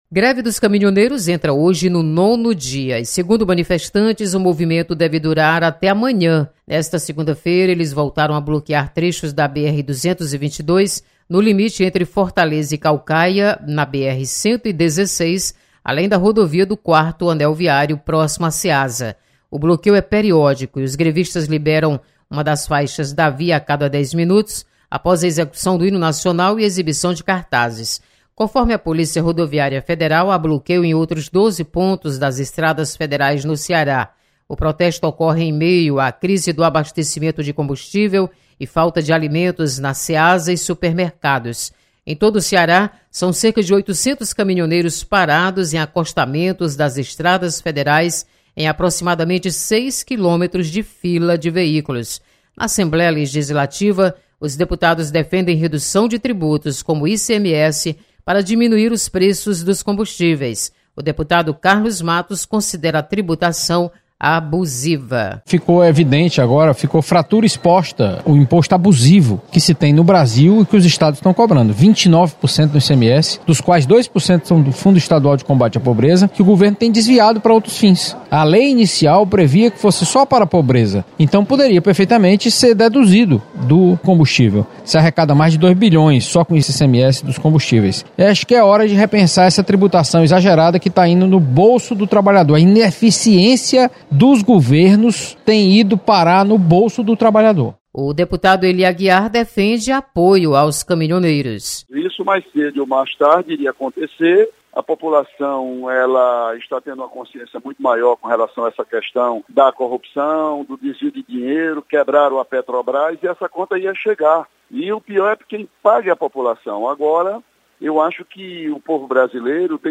Você está aqui: Início Comunicação Rádio FM Assembleia Notícias Greve